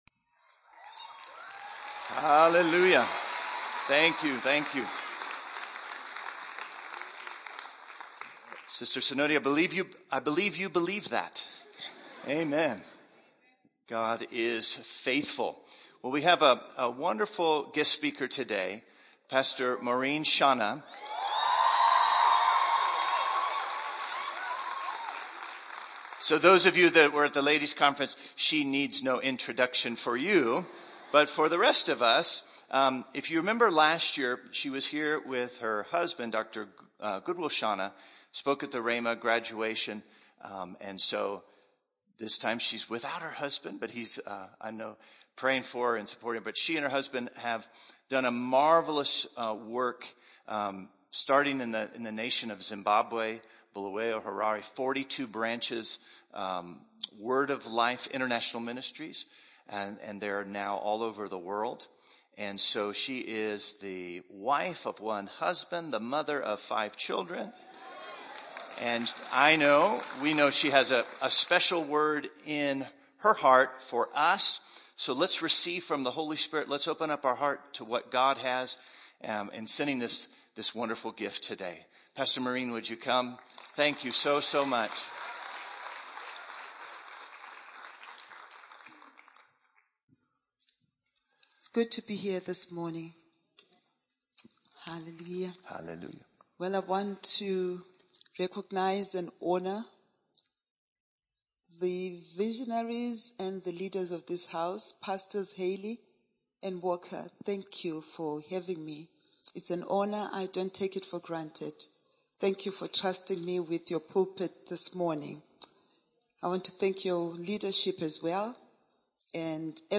Recent Sermon: Spectator to Participator – Connected Through Christ